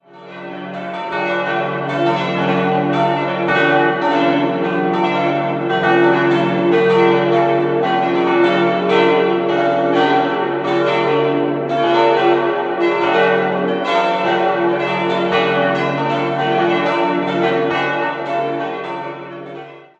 Idealsextett: d'-f'-g'-b'-c''-d'' Die fünf größeren Glocken wurden zwischen 1949 und 1957 von Rudolf Perner in Passau gegossen.